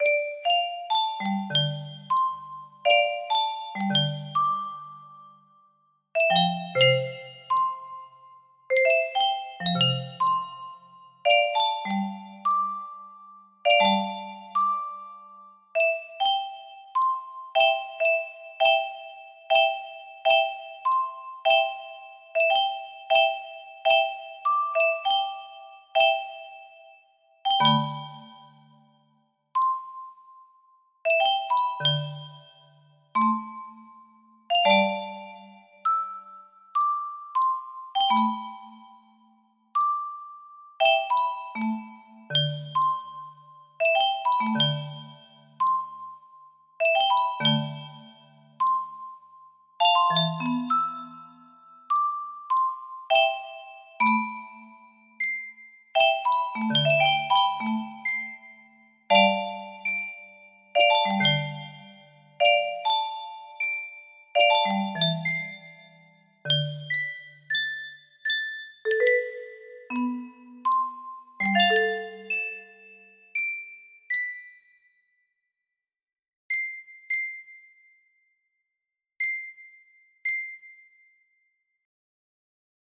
A Hot Mess.... music box melody
Full range 60